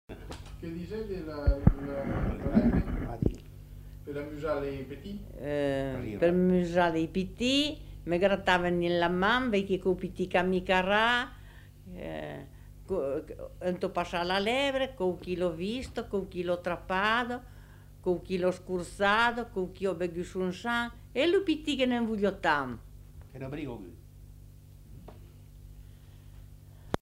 Aire culturelle : Périgord
Lieu : Saint-Rémy-de-Gurson
Effectif : 1
Type de voix : voix de femme
Production du son : récité
Classification : formulette enfantine